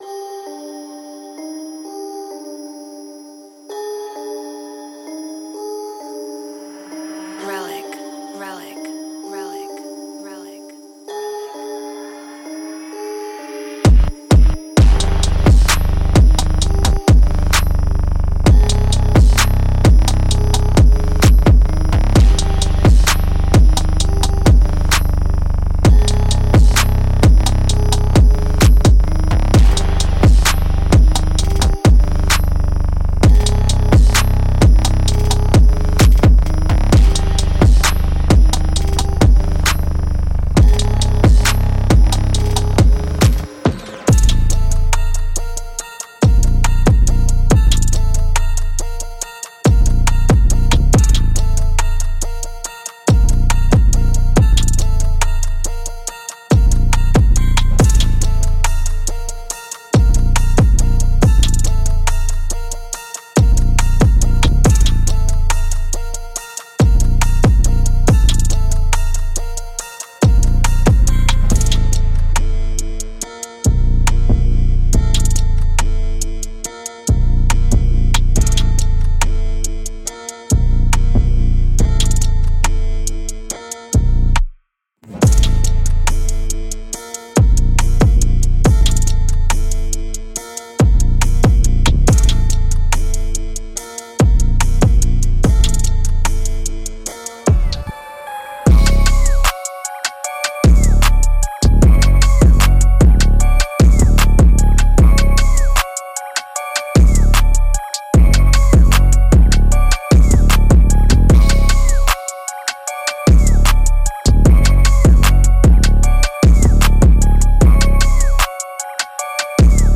每个样本都是经过创造性处理和各种不同类型的失真精心制作而成。
• 25 x 808秒
• 15 x踢
• 10个鼓循环
• 15 x军鼓